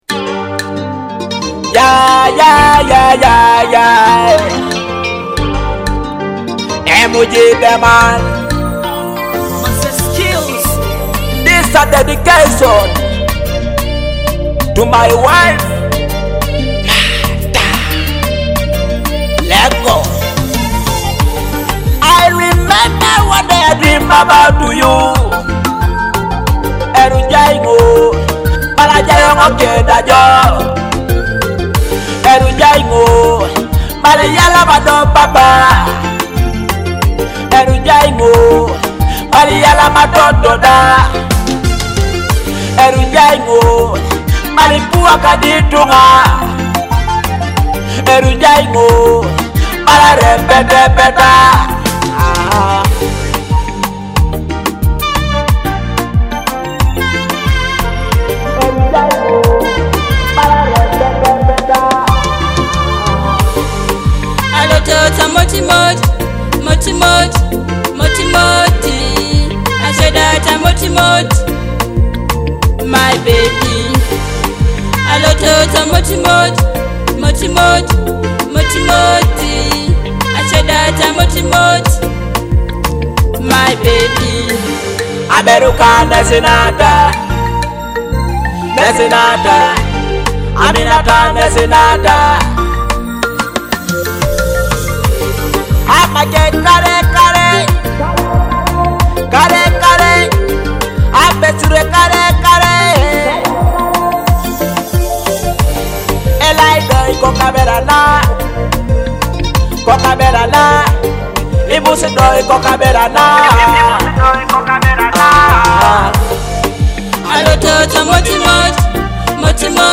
a touching love song dedicated to his wife.